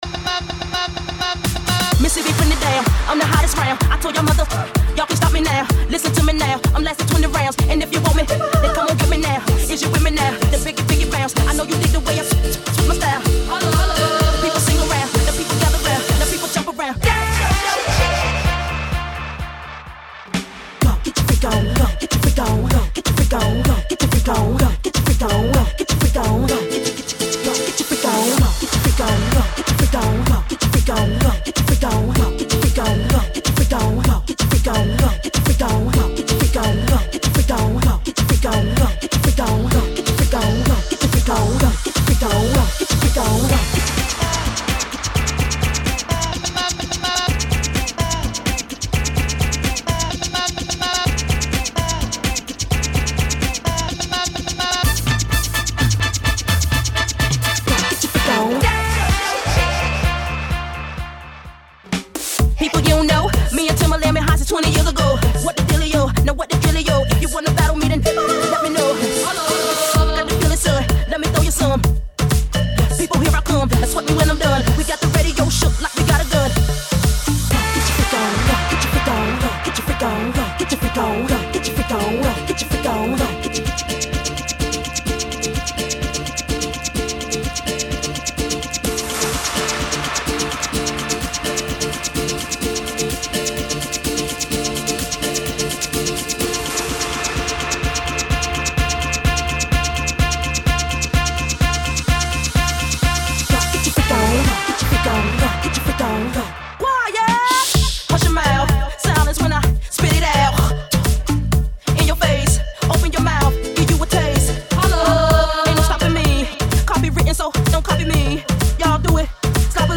Posted in Mashups